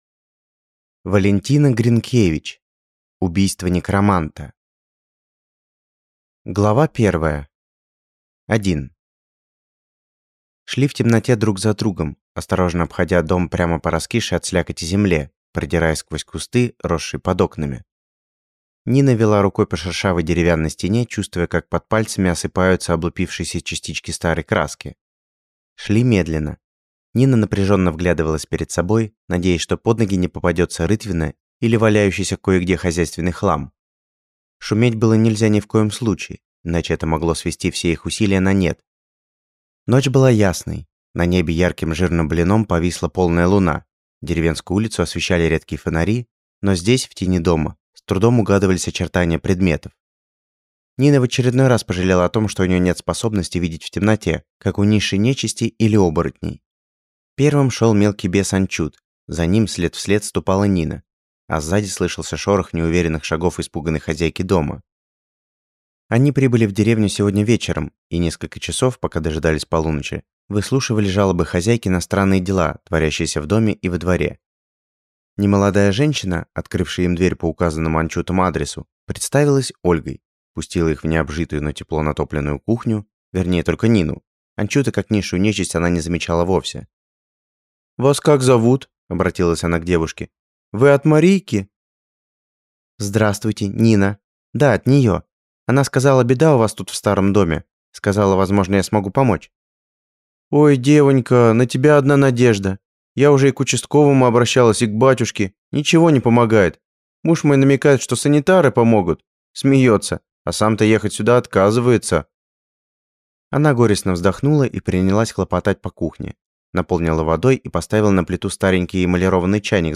Аудиокнига Убийство некроманта | Библиотека аудиокниг